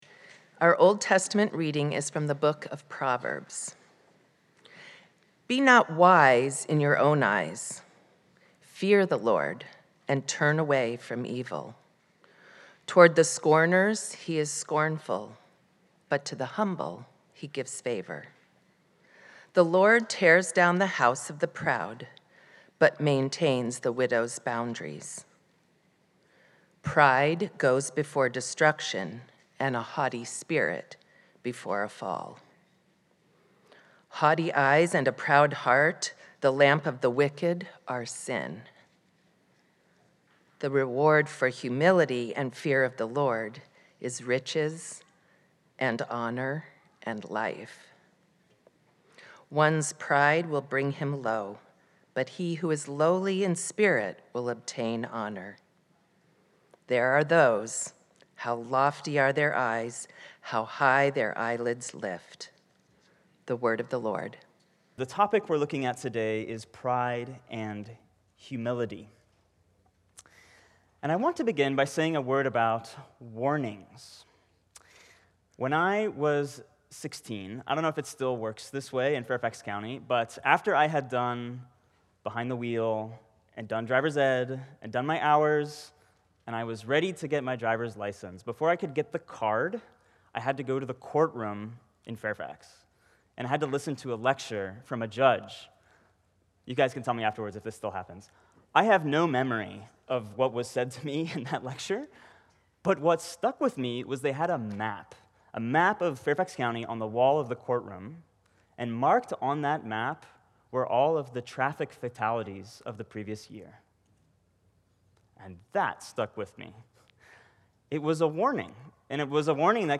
This sermon explores how pride can provide a distorted vision of our own importance that ultimately causes us to fall and experience destruction. However, Proverbs also teaches us to overcome this misplaced pride by focusing on what it means to fear the LORD through humble submission to God’s grace and covenant love.